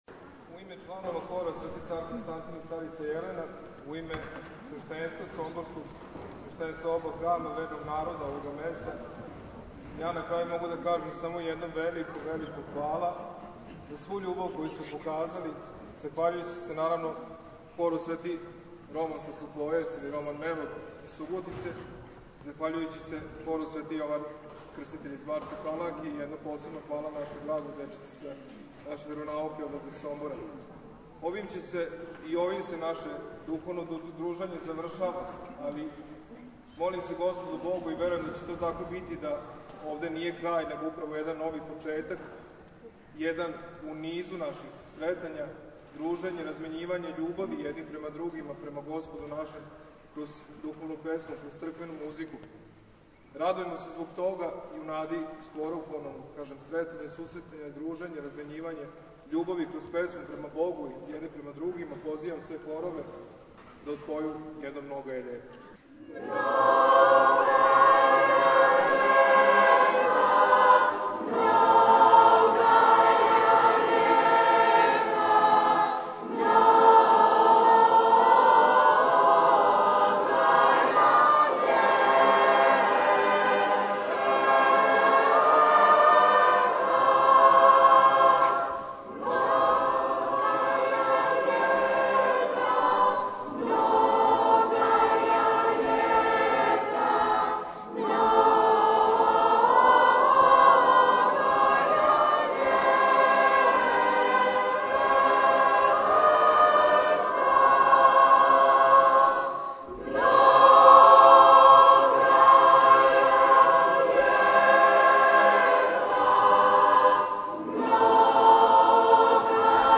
У Сомбору одржана смотра хорова Епархије бачке
zavrsni govor.mp3